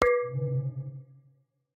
Minecraft Version Minecraft Version latest Latest Release | Latest Snapshot latest / assets / minecraft / sounds / block / end_portal / eyeplace3.ogg Compare With Compare With Latest Release | Latest Snapshot